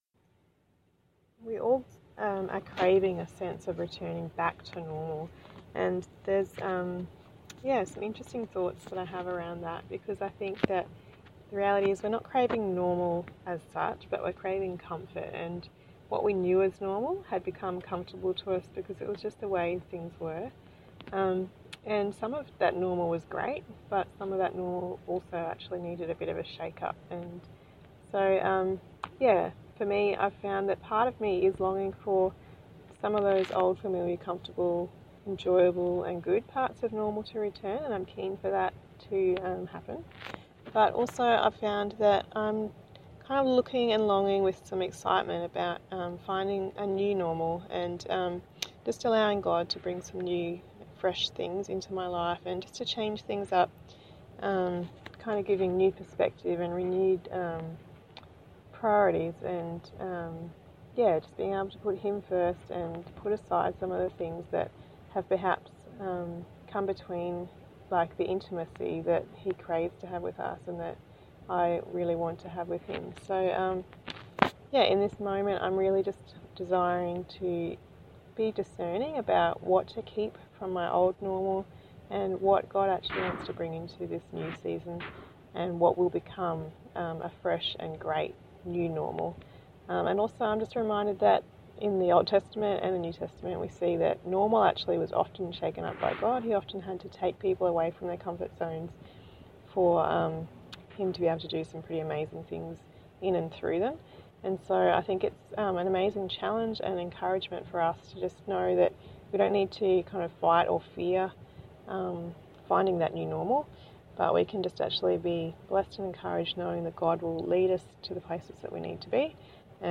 So, I pulled over the car, and spoke into my phone to record my thoughts.
New Normal –  excuse the ‘ums’ and ‘yeahs’ ha ha)…